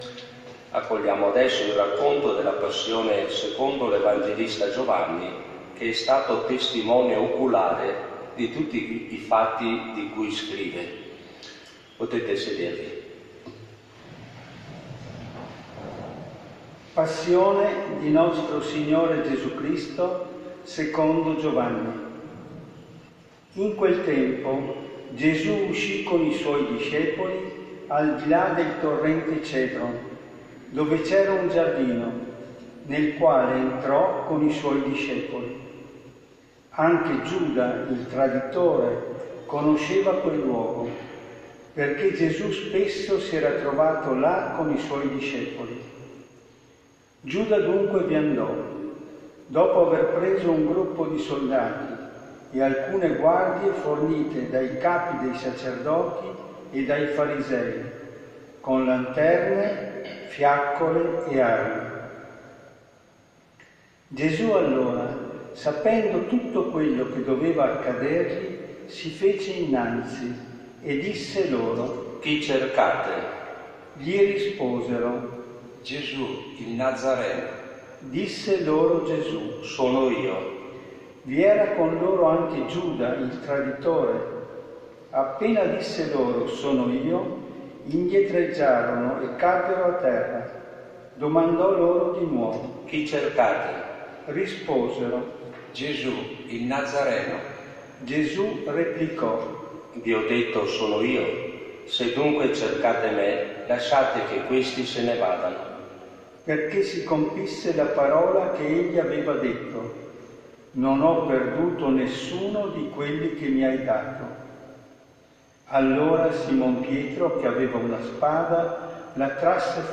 Ascolta la lettura della Passione secondo Giovanni